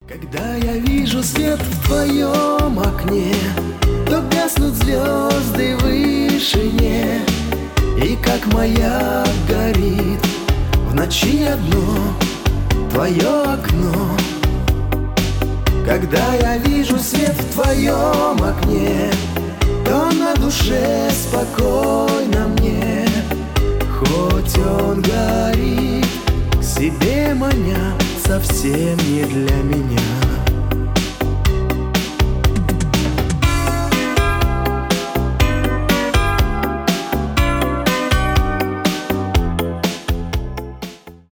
поп
романтические